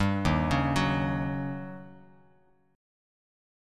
D#7#9 chord